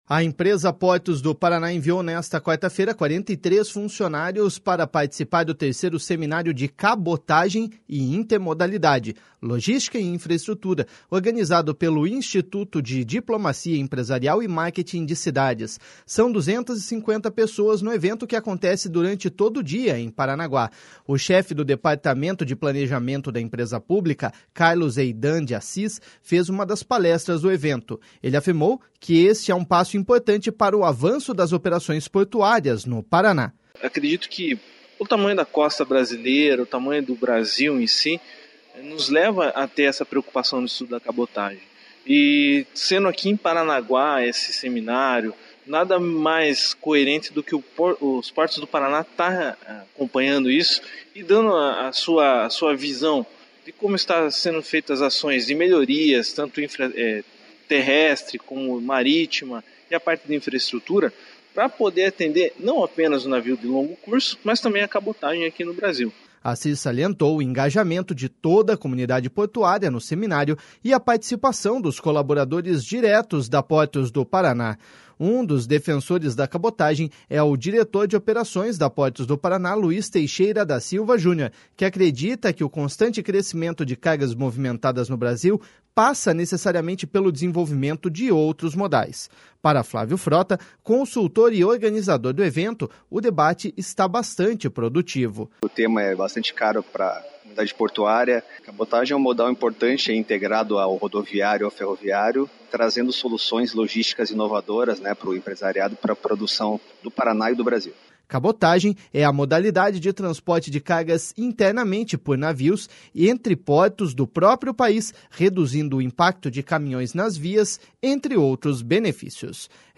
São 250 pessoas no evento que acontece durante todo o dia em Paranaguá.